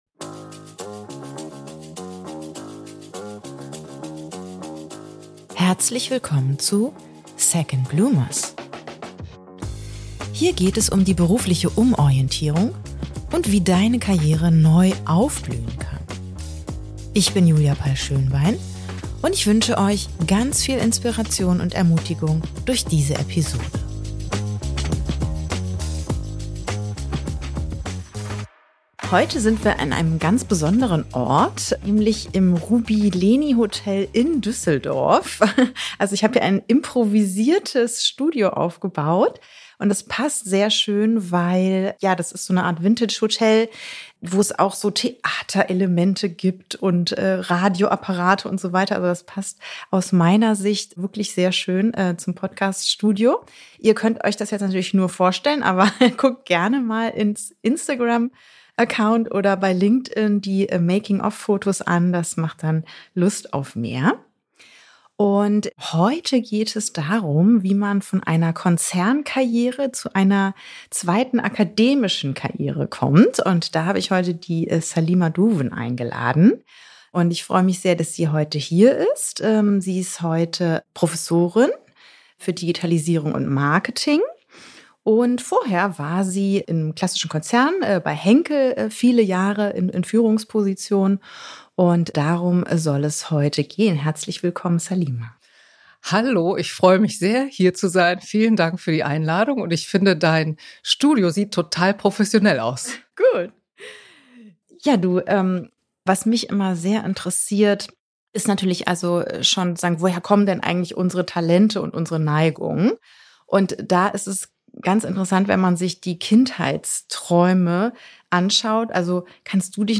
In dieser Episode erwartet euch ein ganz besonderes Gespräch, live aufgenommen im charmanten Ambiente des Ruby Leni Hotels in Düsseldorf.